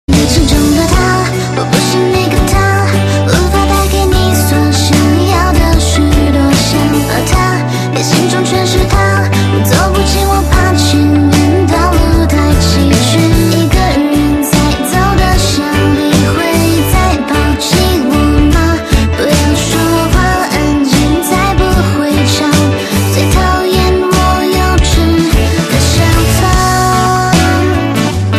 M4R铃声, MP3铃声, 华语歌曲 82 首发日期：2018-05-14 22:37 星期一